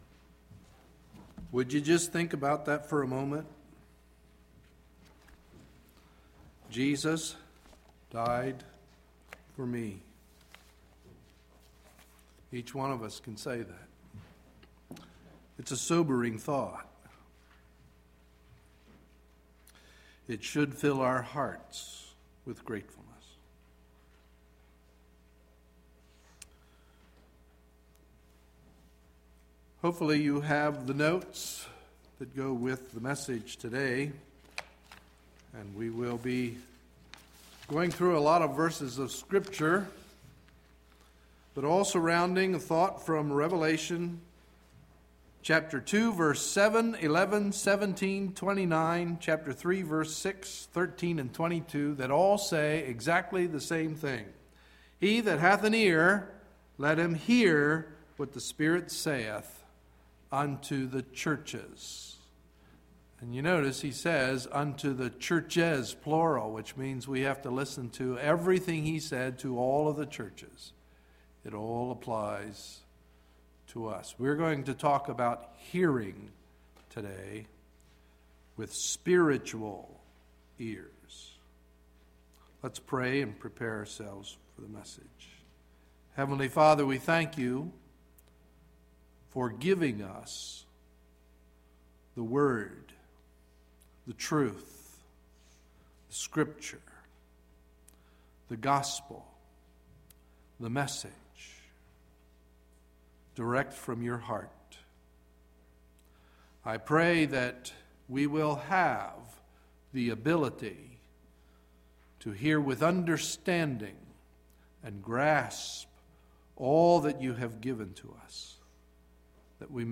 Sunday, March 27, 2011 – Morning Message